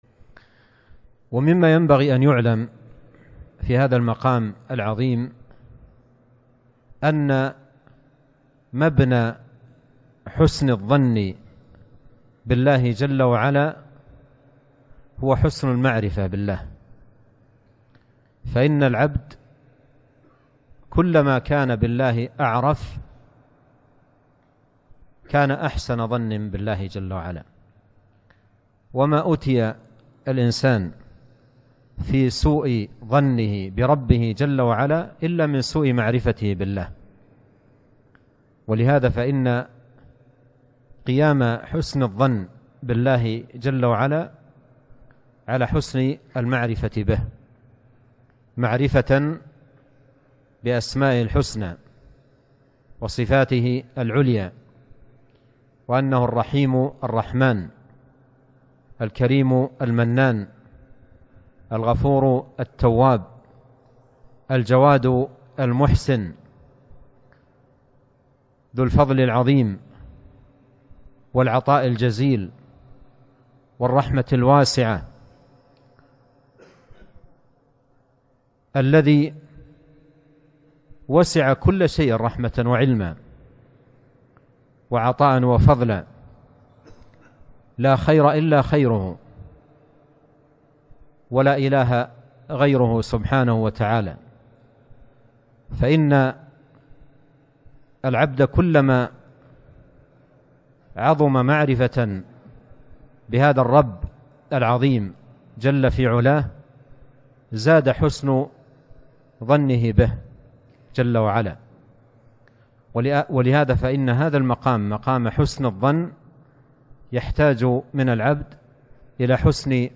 مقطع من محاضرة ماتعة بعنوان: